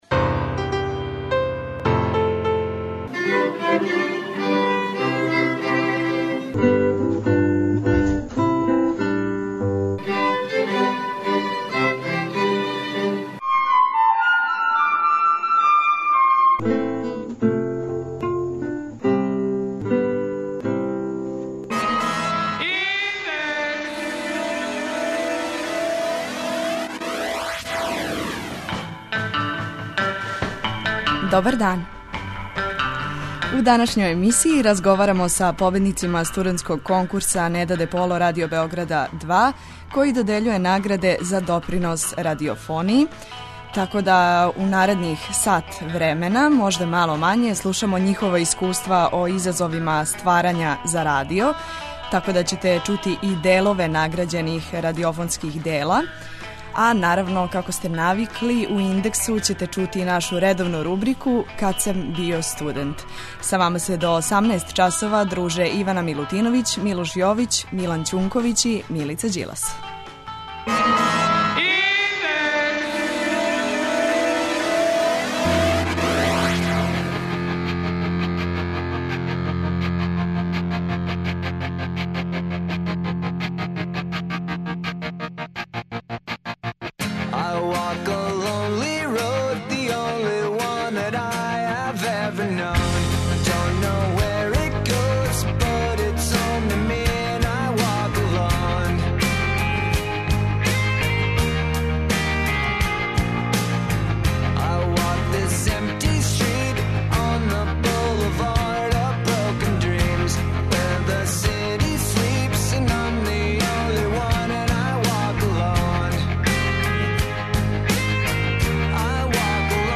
У данашњој емисији разговарамо са победницима студентског конкурса 'Неда Деполо' који додељује награде за допринос радиофонији и слушамо њихова искуства о изазовима стварања за радио. Чућете и нашу редовну рубрику 'Кад сам био студент'.